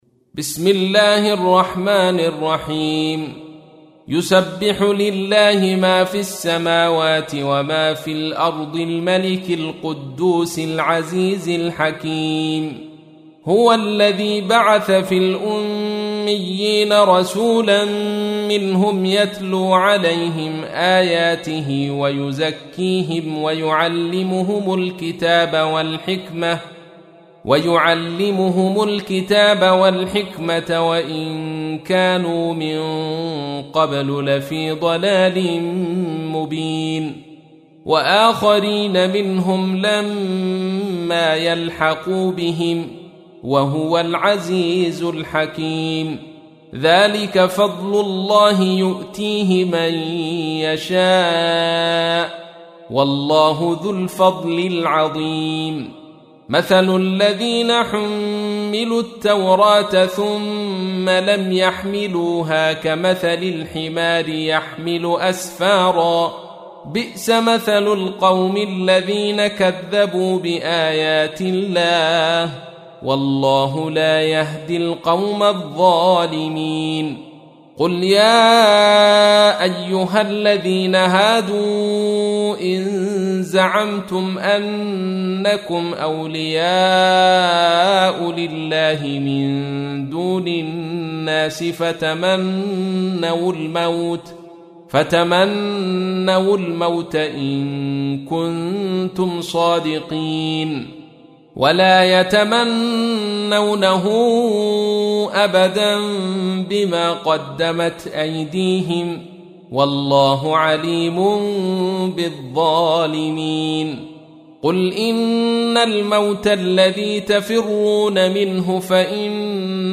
تحميل : 62. سورة الجمعة / القارئ عبد الرشيد صوفي / القرآن الكريم / موقع يا حسين